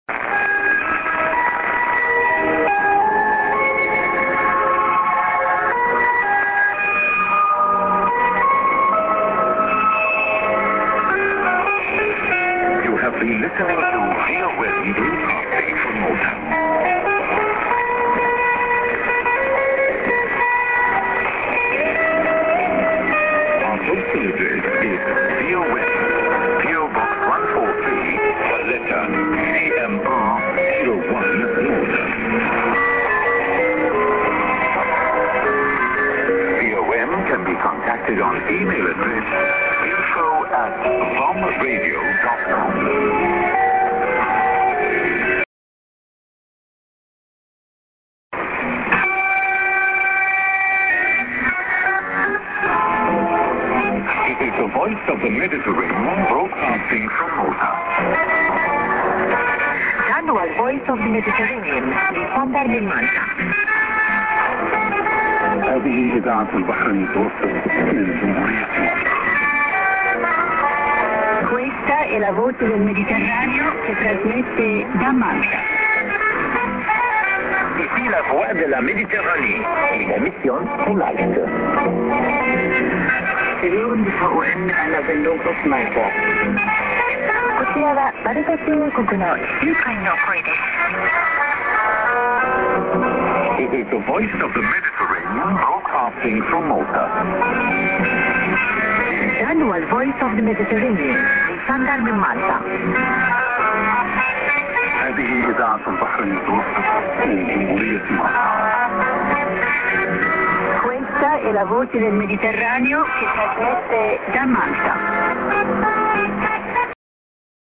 ADDR+eMail ADDR(man)->(A part is cut. )->ID(man;women:各言語によるＩＤ)->